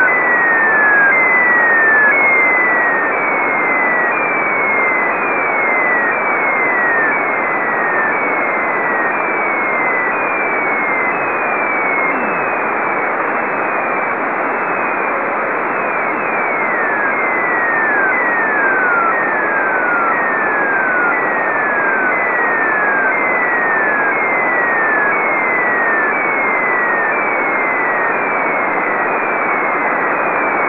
Recordings of the S-band beacon on March 01, 2004 (20th birthday)14:21/23 UT
wav-file recorded with CW demodulation (30 sec)